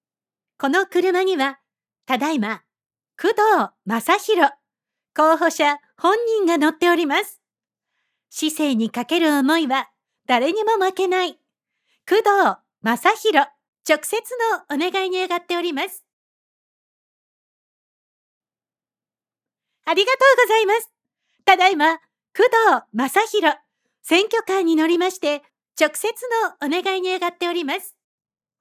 候補者との掛け合いバージョン
ウグイス嬢の録音の間に1分なり5分なり、あらかじめお好きな時間の間隔を開けて吹き込むバージョンです。
録音内容をアレンジすることで、まるで生身のウグイス嬢が乗っているかのように演出することが可能です。
選挙ウグイス嬢のしゃべり方は、特徴的です。